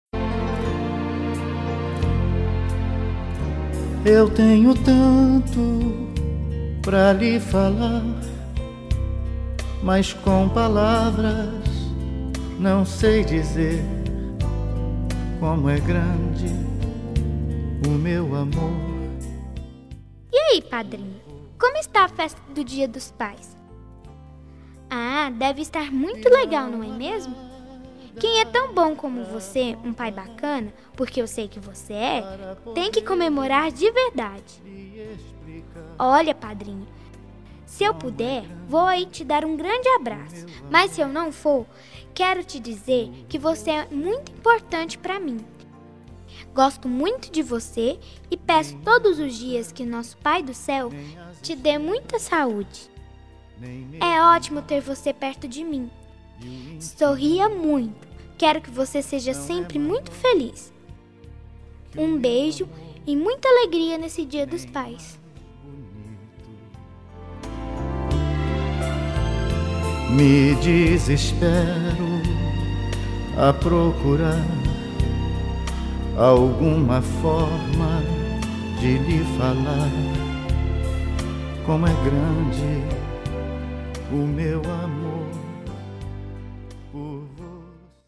Voz de Criança